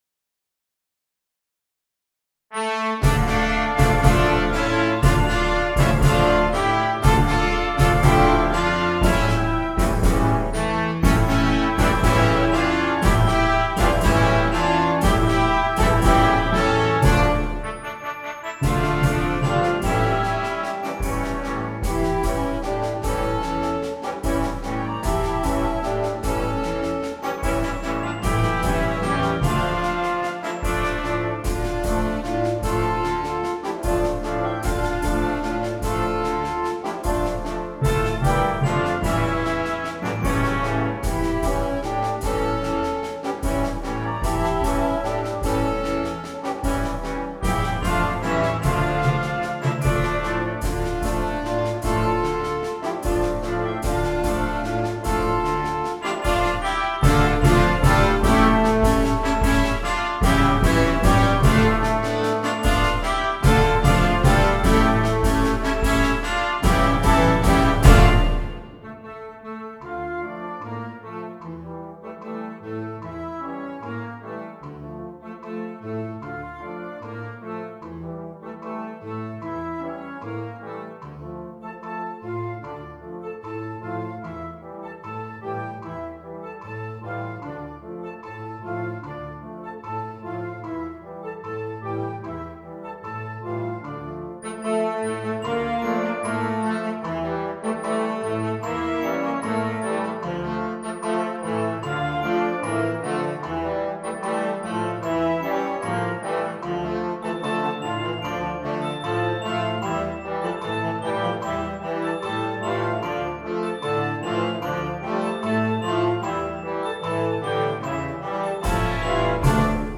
• Flauta
• Oboe
• Clarinete en Bb 1
• Saxofón Alto
• Trompeta en Bb 1
• Trombón
• Tuba
• Glockenspiel
• Redoblante